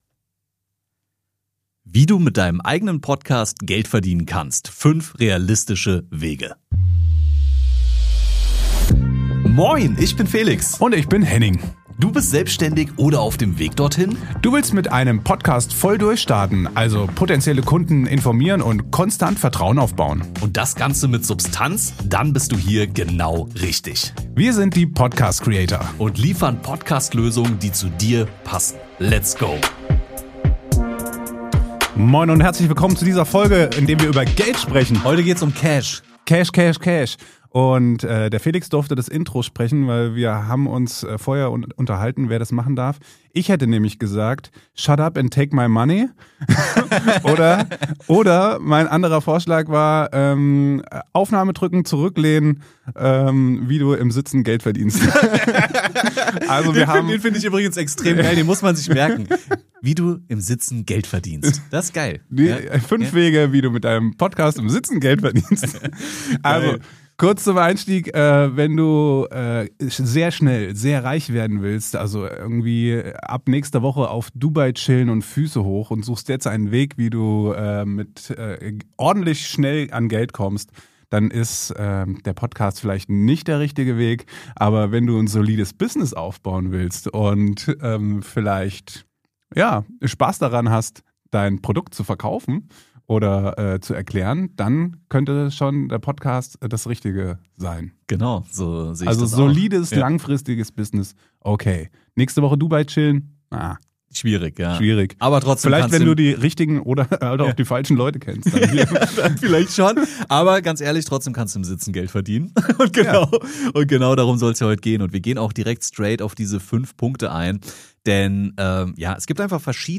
Aufgenommen in der Medienschmiede im Rhein-Main-Gebiet (Taunus, Nähe Frankfurt). Podcast Creator – der Podcast für Selbstständige, Creator & Unternehmer.